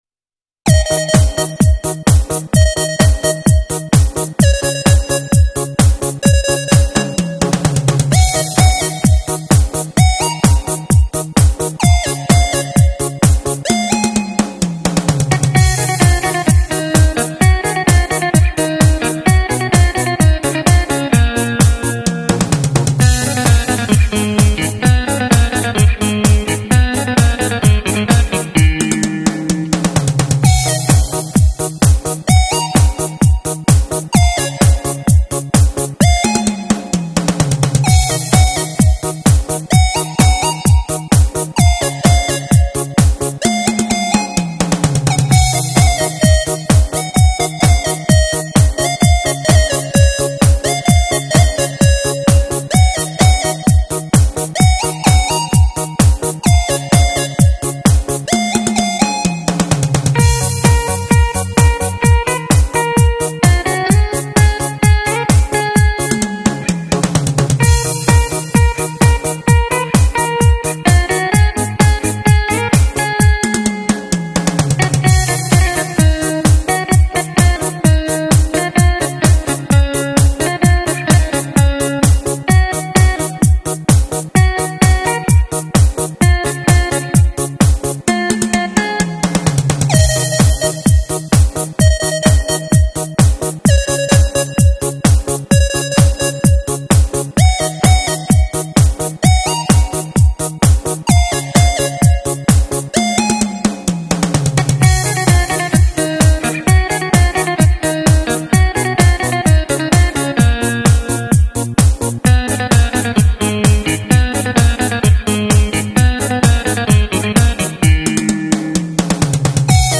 EDM mix house